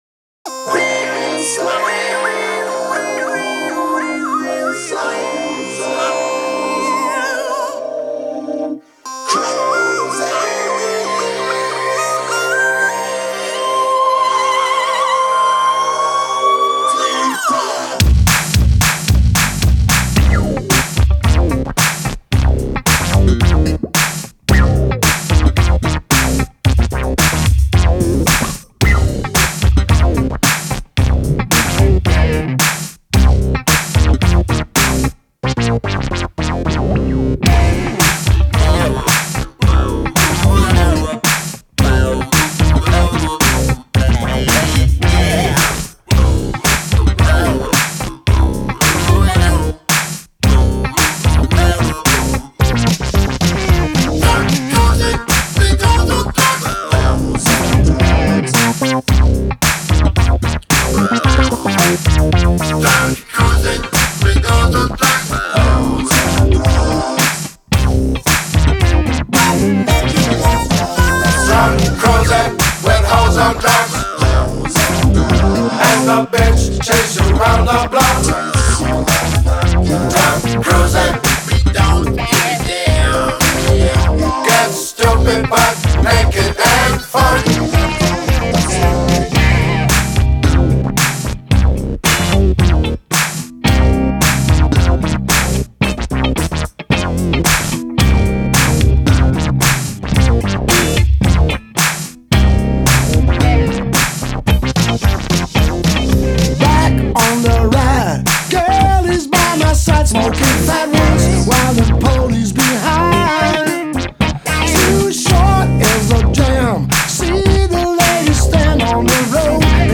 Hypnotische Grooves, virtuose Spielfreude